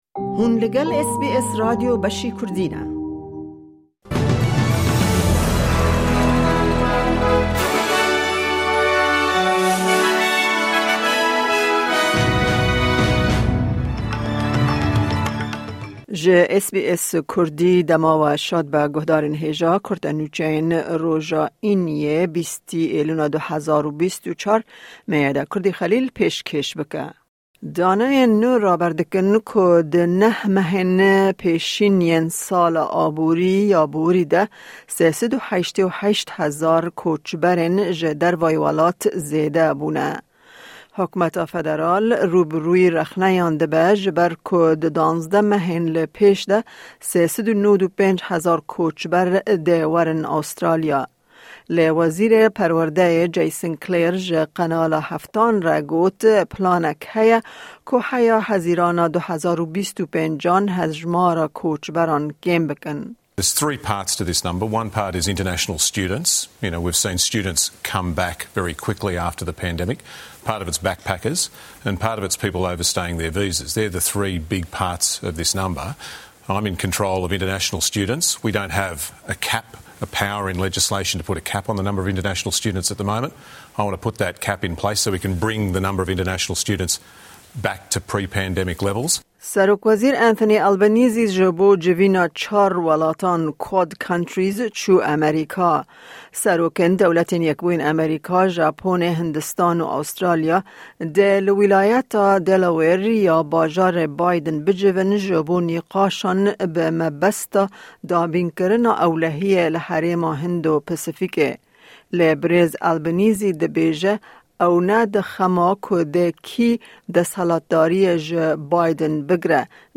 Kurte Nûçeyên roja Înî 20î Îlona 2024